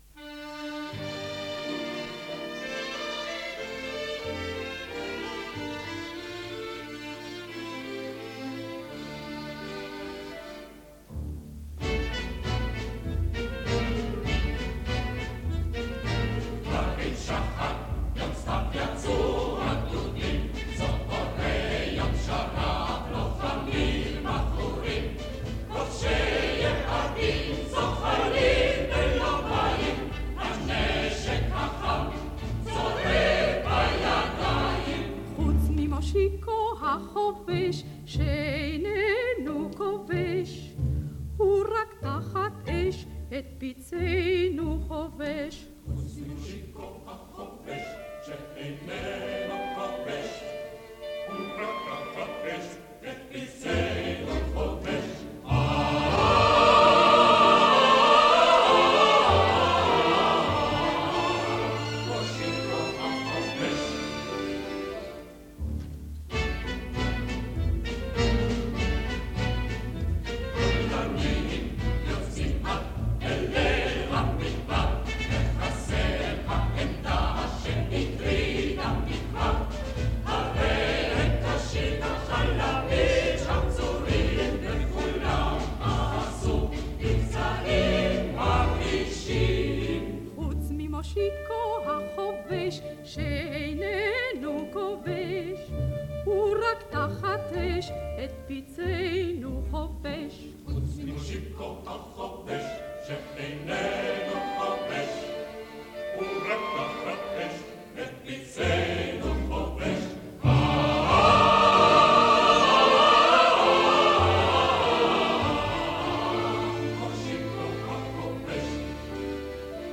מרק לברי, מנצח
מצו-סופרן מקהלת קול ציון לגולה (רשות השידור) תזמורת קול ישראל (רשות השידור)